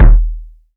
KICK.61.NEPT.wav